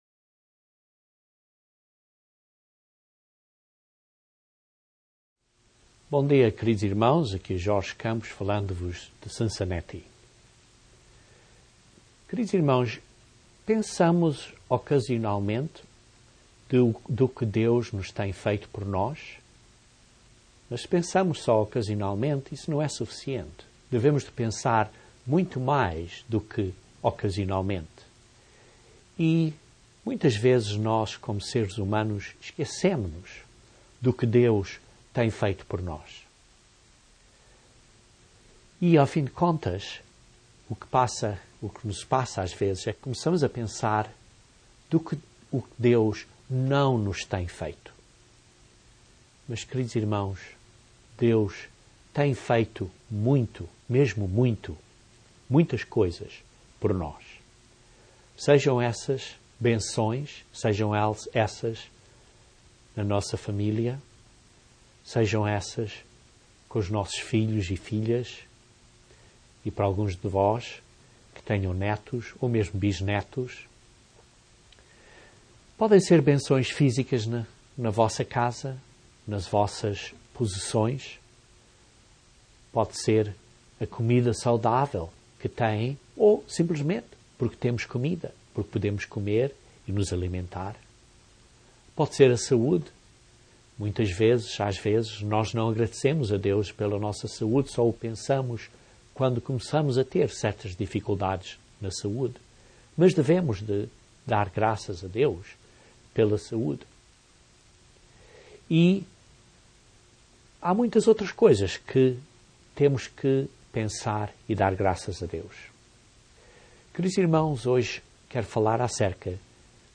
Dar graças a Deus é parte dum 'sacrifício' que é agradável a Deus. Este sermão analisa a 'oferta pacífica' e compara com as instruções no Novo Testamento de darmos um 'sacrifício de acção de graças'.